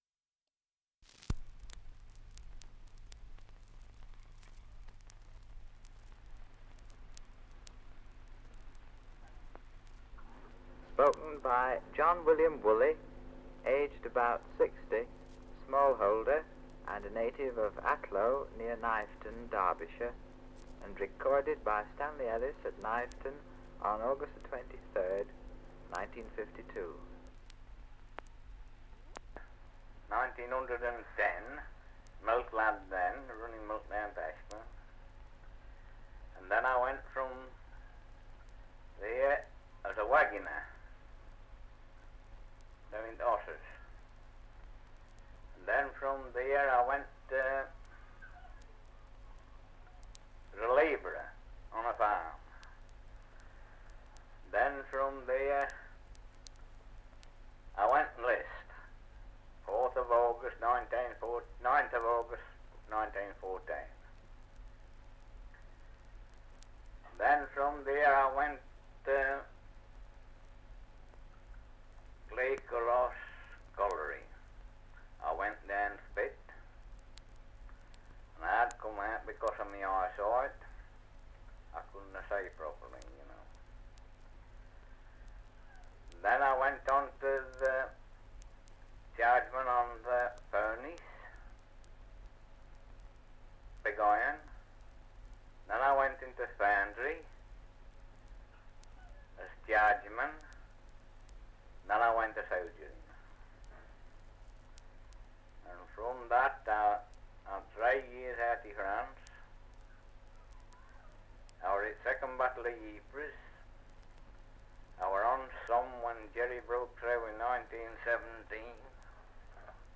Survey of English Dialects recording in Kniveton, Derbyshire
78 r.p.m., cellulose nitrate on aluminium